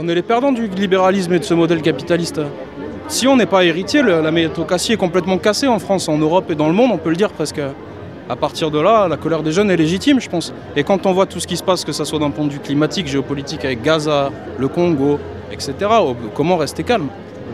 Étudiants, fonctionnaires, retraités, ouvriers : Ils étaient environ un millier à s’être réunis hier pour la manifestation à Mende dans le cadre du mouvement national de grève du 18 septembre.